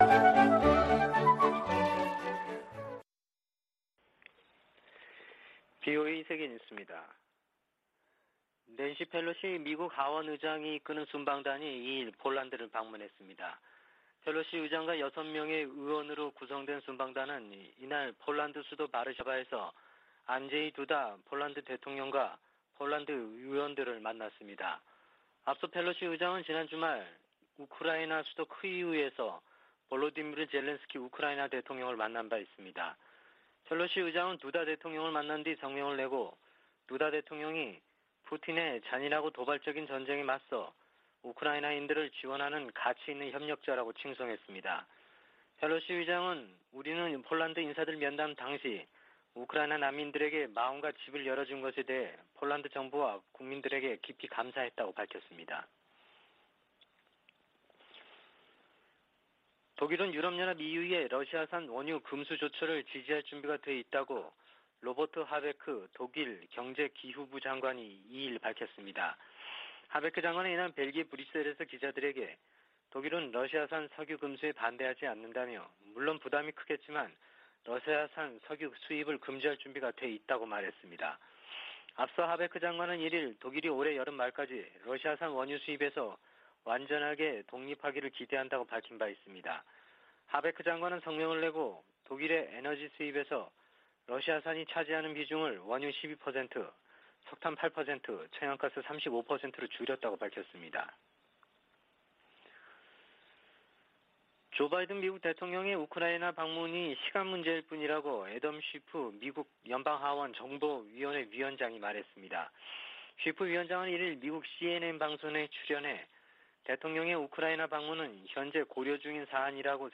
VOA 한국어 아침 뉴스 프로그램 '워싱턴 뉴스 광장' 2022년 5월 3일 방송입니다. 미 국무부가 핵실험 준비 동향이 포착된 북한에 대해 역내에 심각한 불안정을 초래한다고 지적하고 대화를 촉구했습니다. 북한 풍계리 핵실험장 3번 갱도 내부와 새 입구 주변에서 공사가 활발히 진행 중이라는 위성사진 분석이 나왔습니다. 빌 해거티 미 상원의원은 조 바이든 대통령의 한일 순방이 인도태평양 지역 적국들에 중요한 신호를 보낼 것이라고 밝혔습니다.